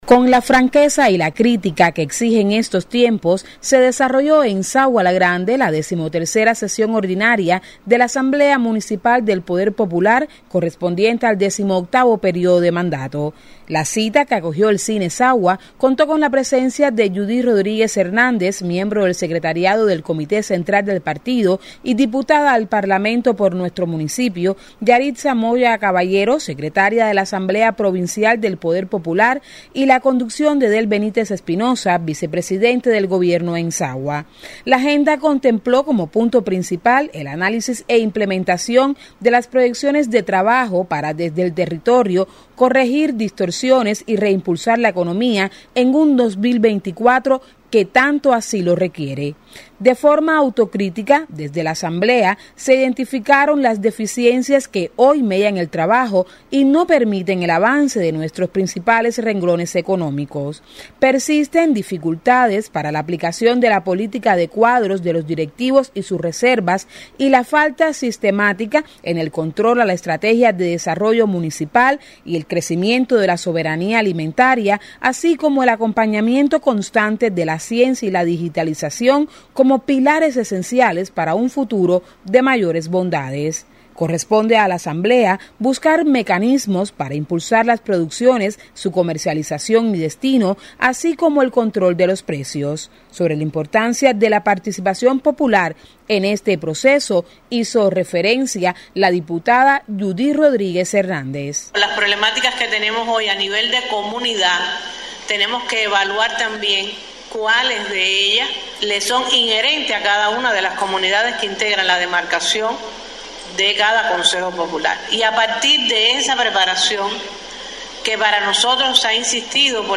Escuche más detalles en voz de la periodista